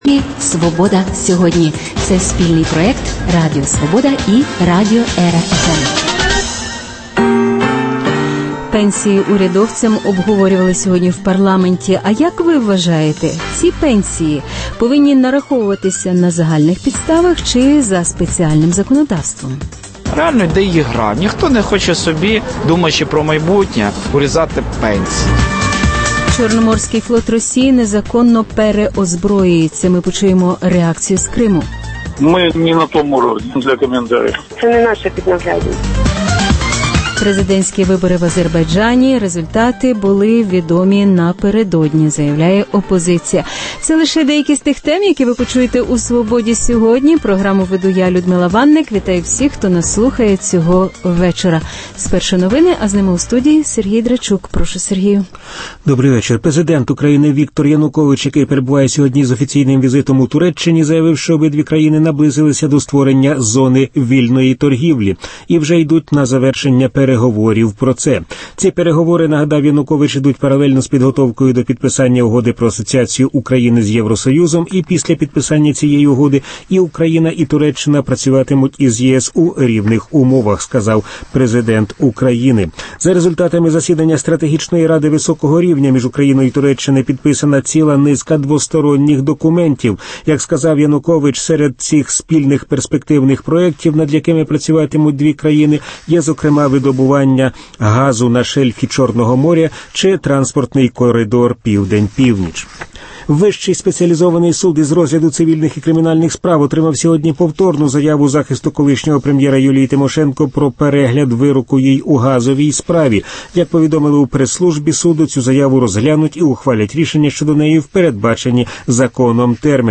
Наслідки атаки на Державні реєстри і висновки, які потрібно зробити на майбутнє Чорноморський флот Росії незаконно переозброюється – реакція в Криму Інтерв’ю з письменницею Тетяною Малярчук